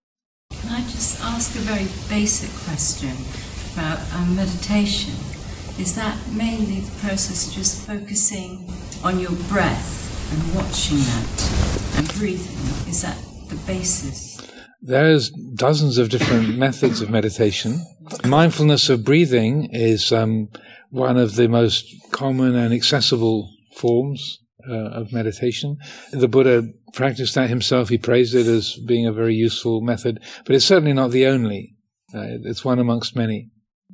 5. “Is meditation mainly the process of just focusing on your breath and watching it?” Answered by Ajahn Amaro.